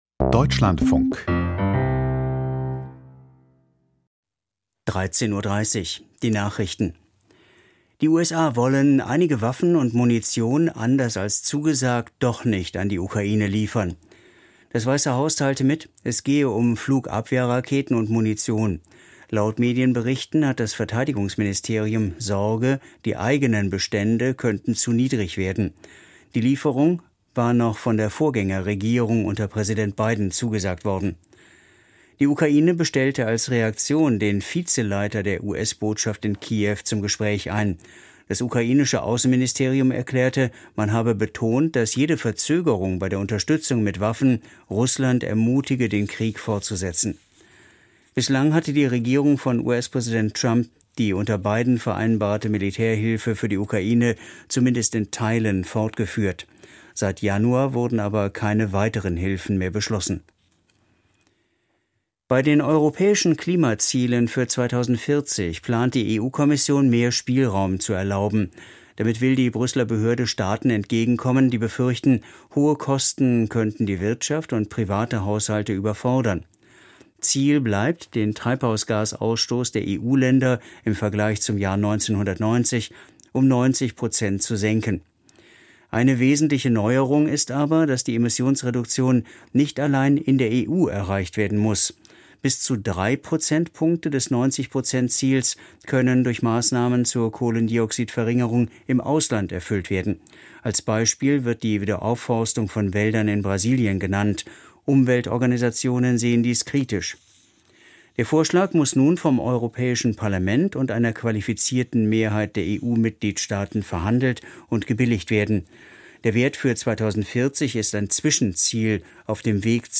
Die Nachrichten vom 02.07.2025, 13:30 Uhr